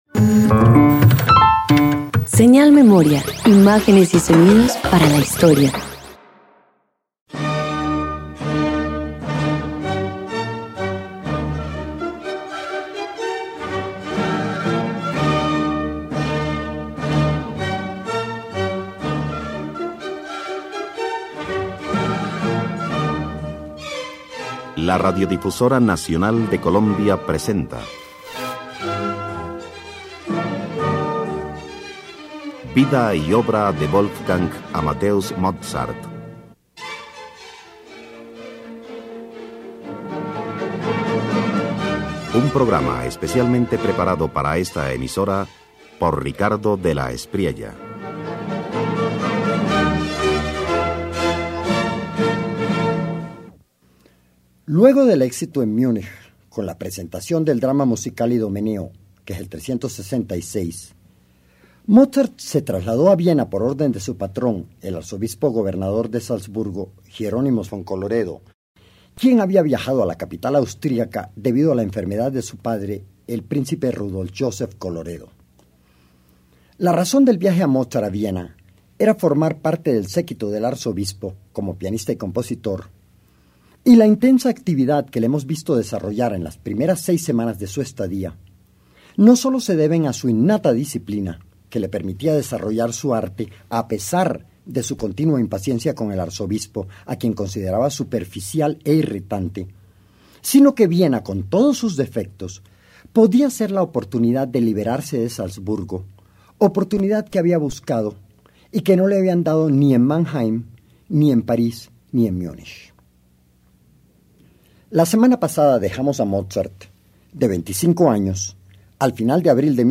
En Viena, Mozart rompe con Salzburgo y abraza su libertad creativa componiendo variaciones llenas de ingenio que exploran temas franceses. El inicio de una etapa decisiva de su madurez musical protagonizada por el diálogo entre piano y violín.